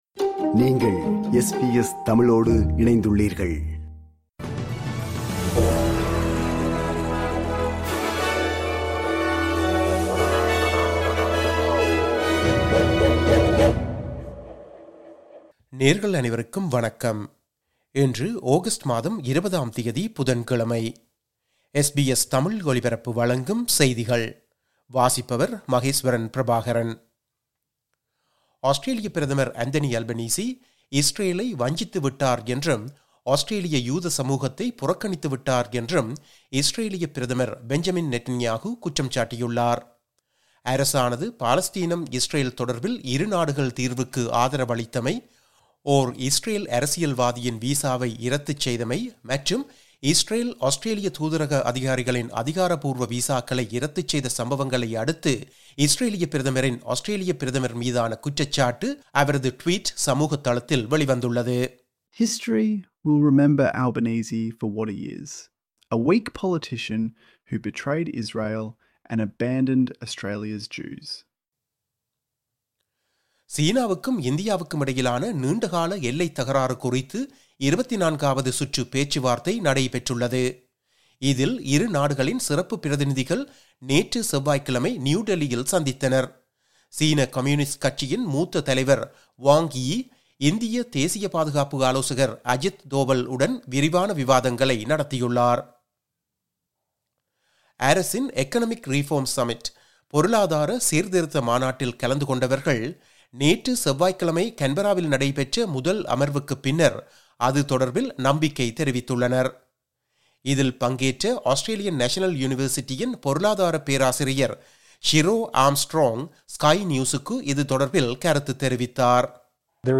SBS தமிழ் ஒலிபரப்பின் இன்றைய (புதன்கிழமை 20/08/2025) செய்திகள்.